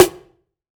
SNARE 042.wav